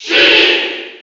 Sheik_Cheer_NTSC_SSB4.ogg